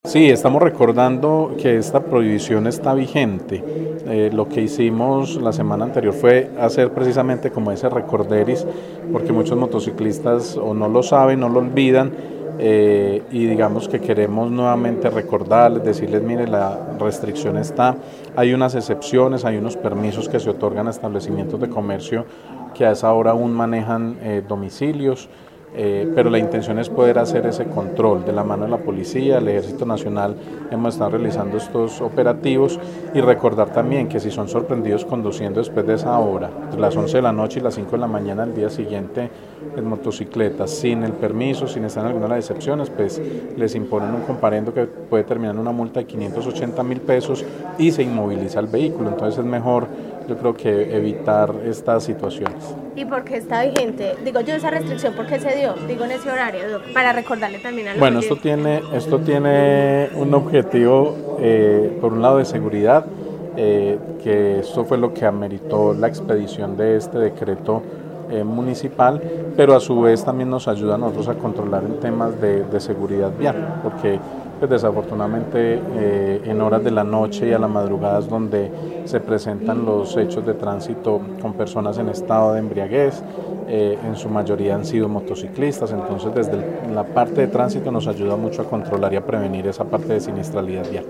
Secretario de Tránsito de Armenia, Daniel Jaime Castaño